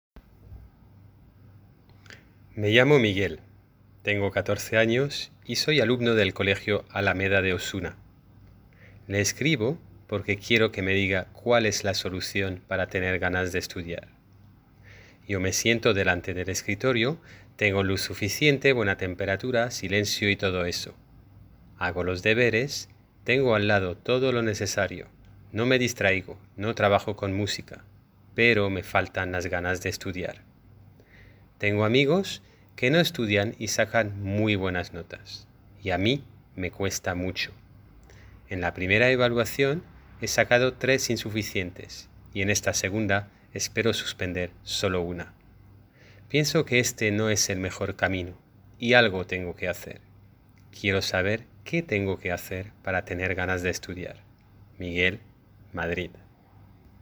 Lecturas ejemplos
Lectura completa, "de un tirón"